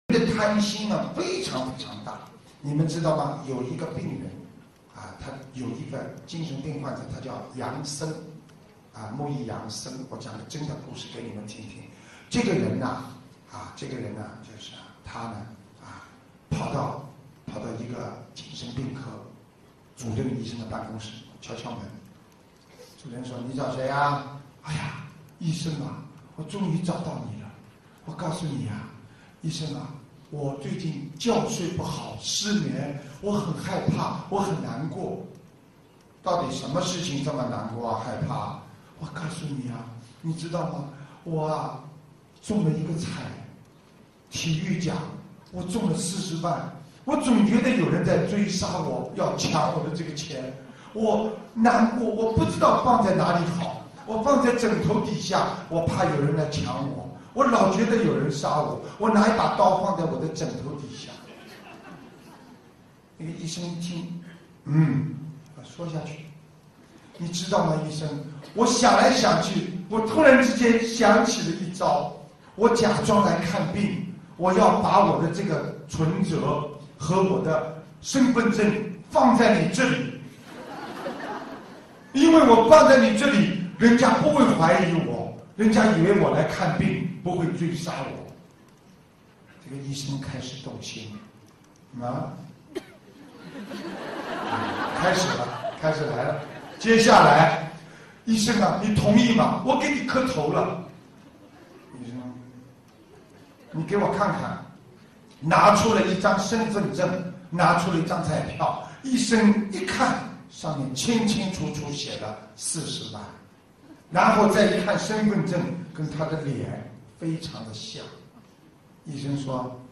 音频：医生和患者的对话·师父讲小故事大道理